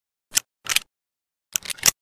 kiparis_reload.ogg